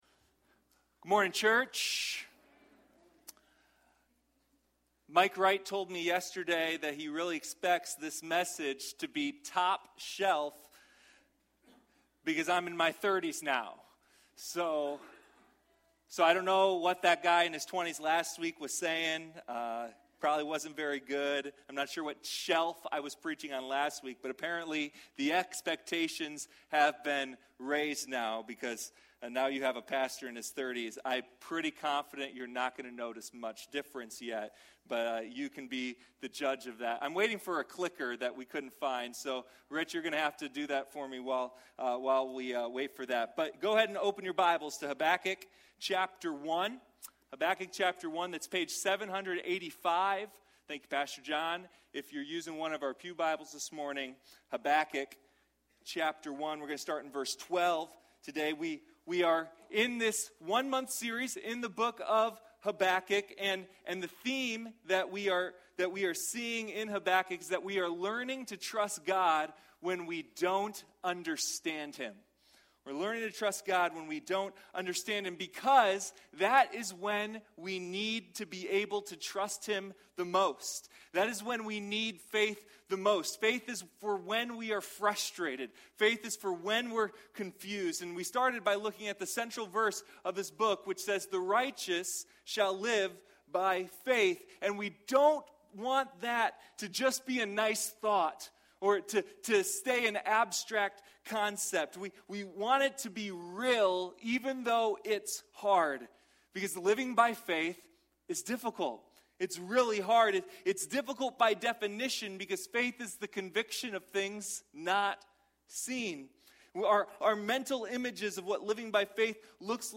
Waiting at the Watchtower – First Baptist Church